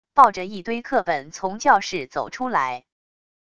抱着一堆课本从教室走出来wav音频